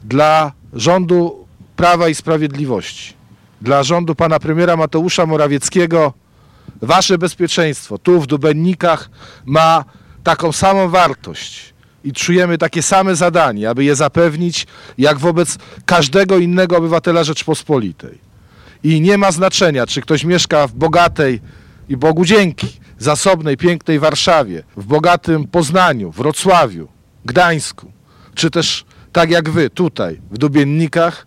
Obecny na uroczystości Joachim Brudziński, szef MSWiA zapewniał, że dla rządu PiS bezpieczeństwo mieszkańców Dubeninek ma taką samą wartość, jak każdego innego obywatela Rzeczpospolitej.